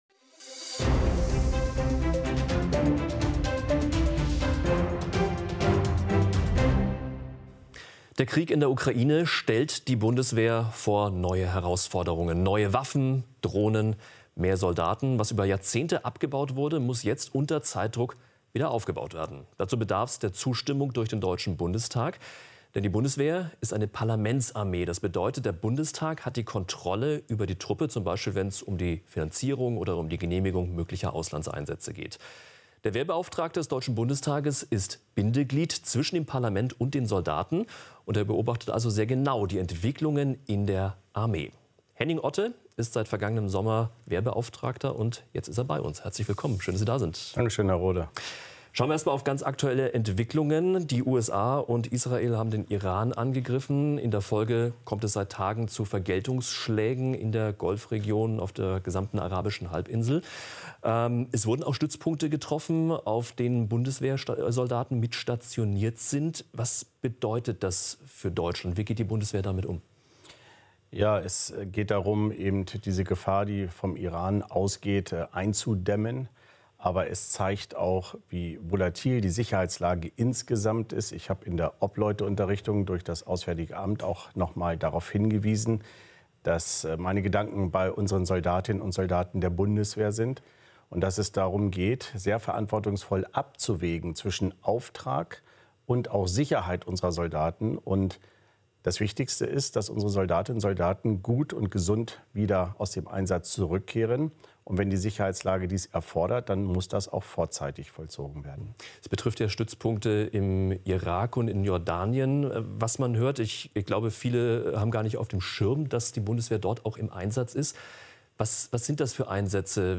Interview Wehrbeauftragter Otte Jahresbericht 2025